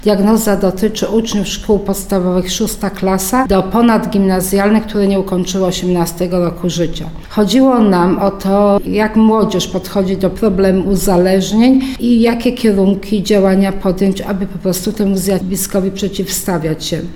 Więcej o spotkaniu przed jego rozpoczęciem powiedziała nam wiceburmistrz Żnina Aleksandra Nowakowska.